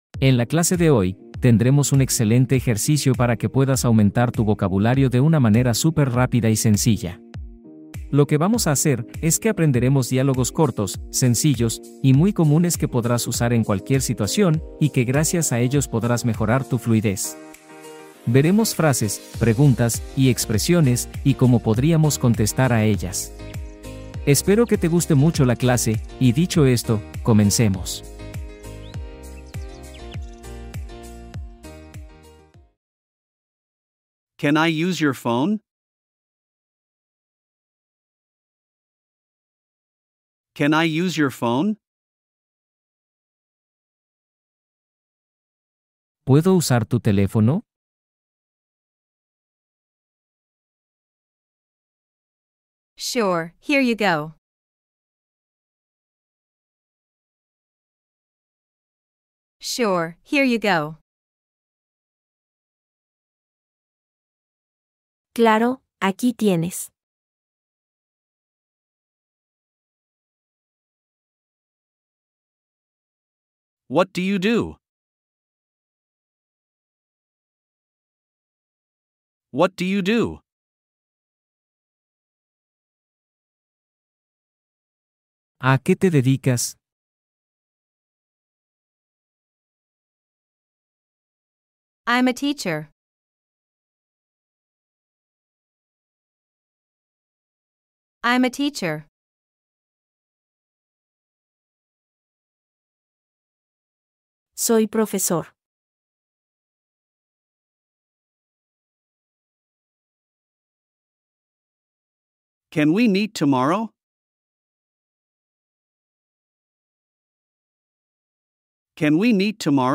✅ +100 diálogos prácticos para mejorar tu listening en inglés – repite y aprende fácil